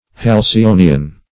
Halcyonian \Hal`cy*o"ni*an\ (h[a^]l`s[i^]*[=o]"n[i^]*an), a.